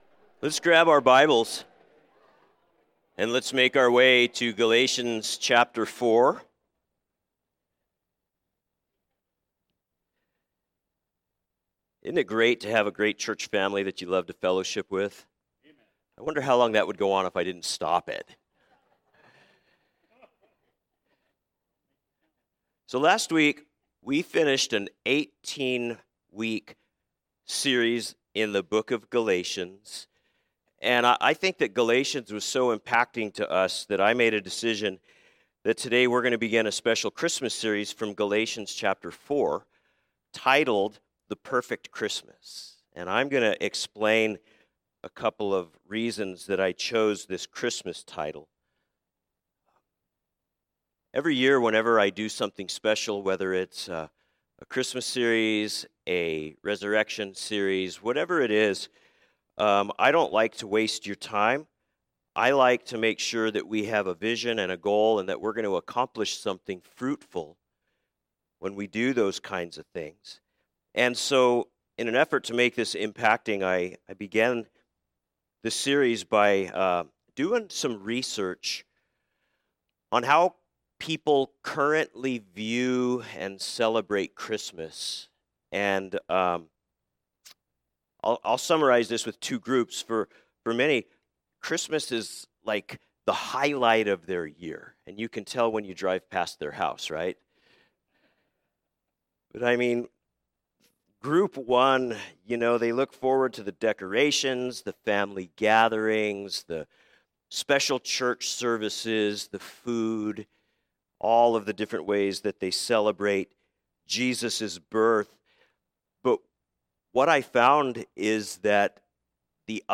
A message from the series "Wednesday Evening." Cross References: Galatians 4, John 1, Romans 5